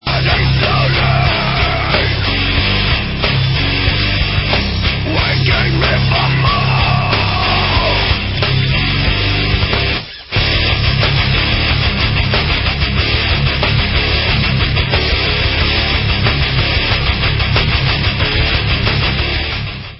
sledovat novinky v oddělení Heavy Metal